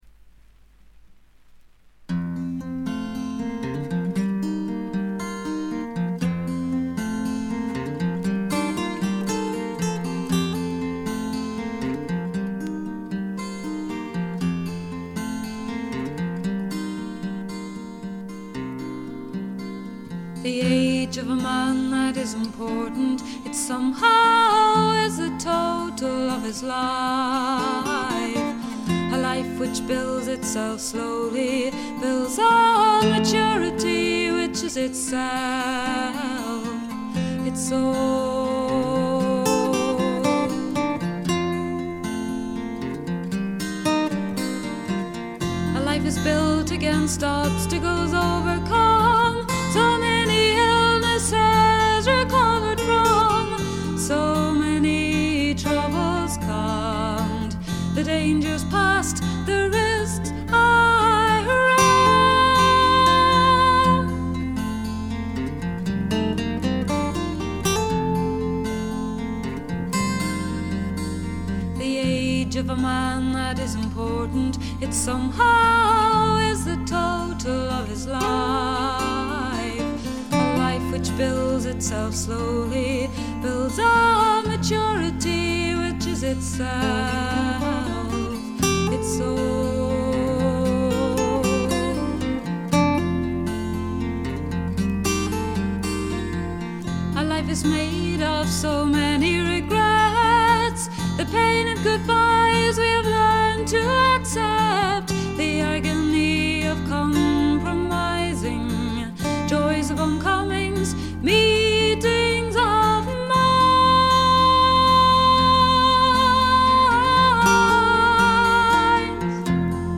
微細なチリプチがほんの少し。
試聴曲は現品からの取り込み音源です。
Cello
Vocals, Acoustic Guitar